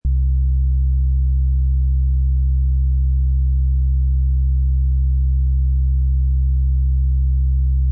mnl-solid step triangle wave.mp3